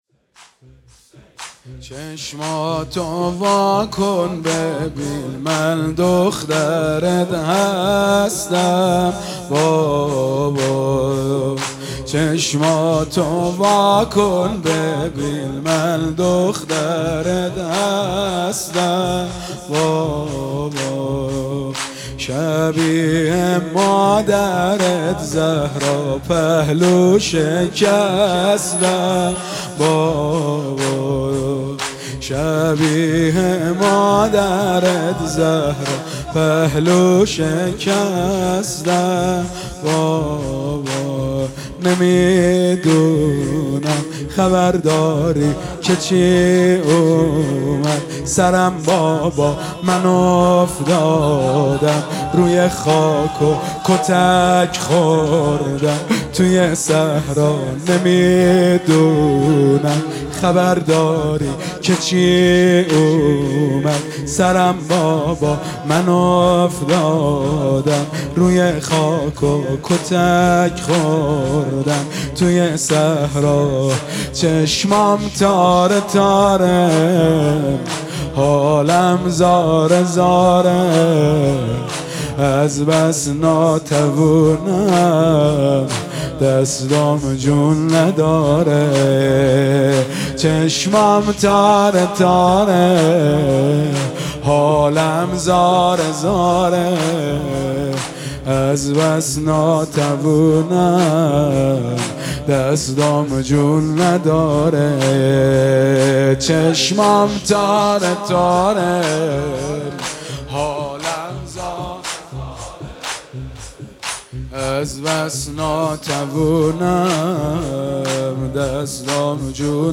مداحی شب سوم محرم
در هیئت عبدالله بن الحسن